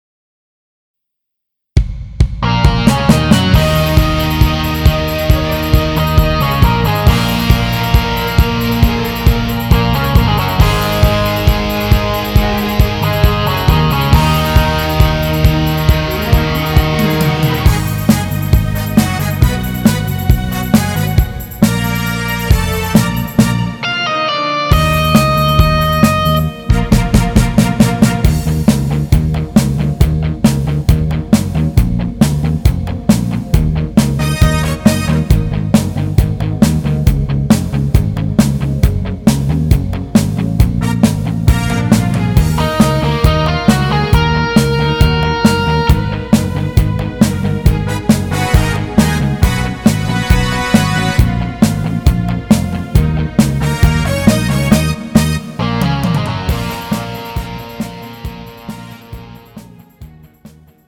음정 남자-2키
장르 가요 구분 Pro MR